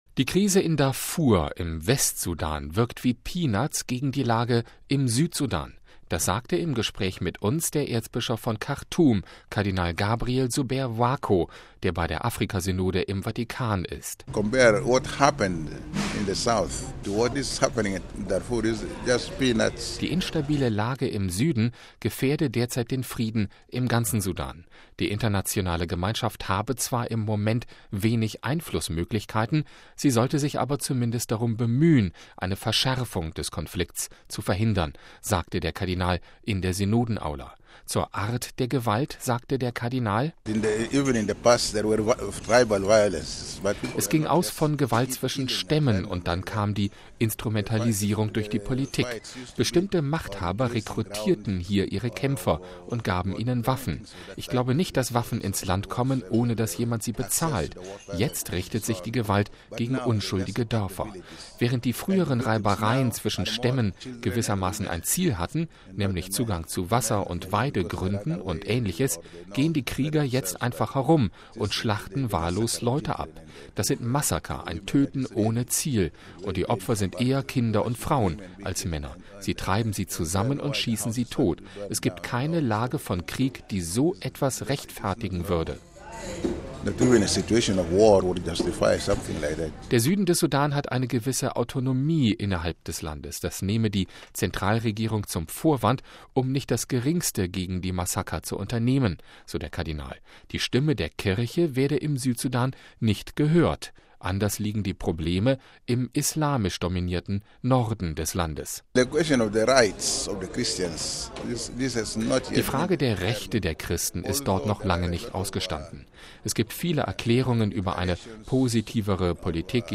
Das sagte im Gespräch mit uns der Erzbischof von Khartoum, Kardinal Gabriel Zubeir Wako, der bei der Afrikasynode im Vatikan vertreten ist. Die instabile Situation im Süden gefährde derzeit den Frieden im ganzen Land.